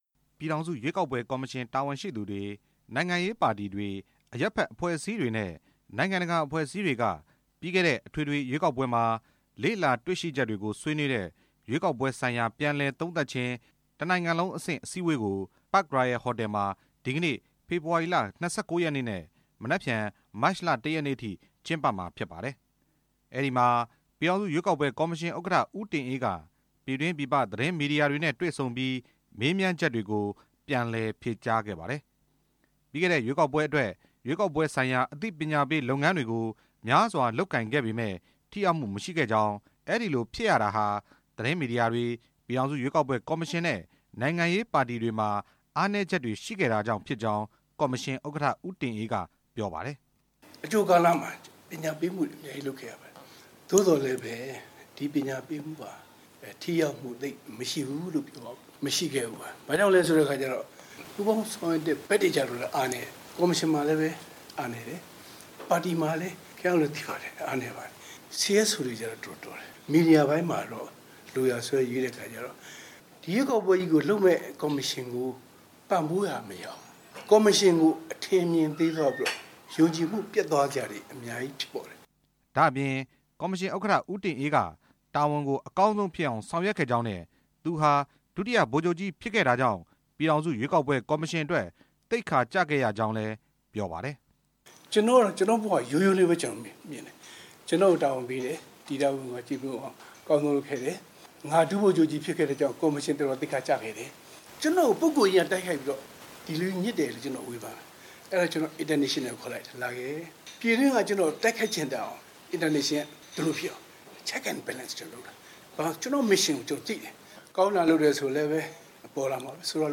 ဒီကနေ့ ရန်ကုန်မြို့ Park Royal ဟိုတယ်မှာပြုလုပ်တဲ့ ရွေးကောက်ပွဲဆိုင်ရာ ပြန်လည်သုံးသပ်ခြင်း နိုင်ငံလုံးအဆင့် အစည်းအဝေး အခမ်းအနားမှာ သတင်းထောက်တွေနဲ့ တွေ့ဆုံစဉ် ကော်မရှင် ဥက္ကဌ ဦးတင်အေး က ပြောလိုက် တာ ဖြစ်ပါတယ်။